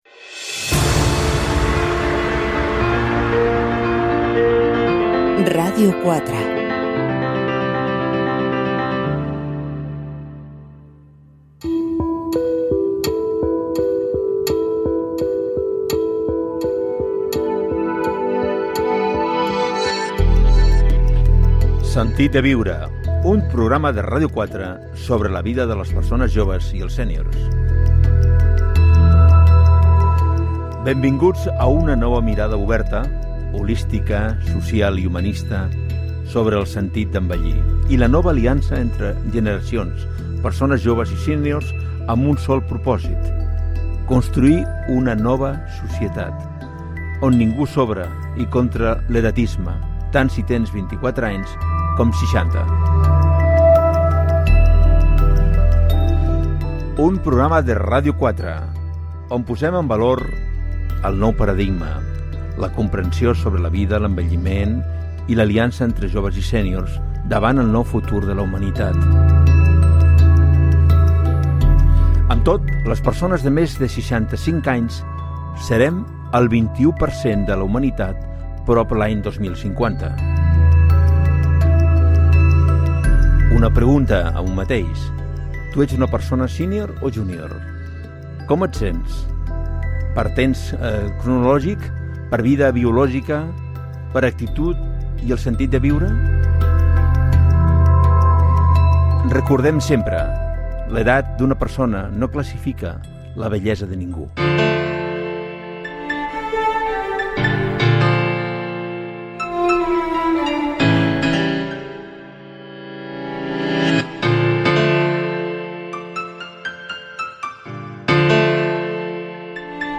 Indicatiu de la ràdio
Gènere radiofònic Divulgació